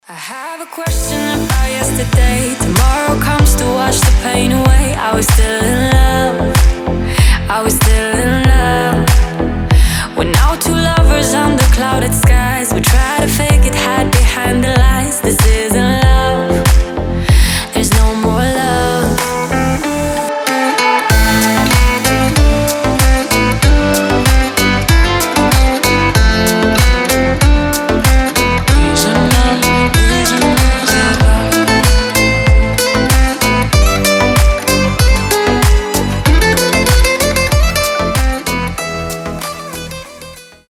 • Качество: 320, Stereo
гитара
deep house
женский голос
восточные
Красивая танцевальная музыка с восточными мотивами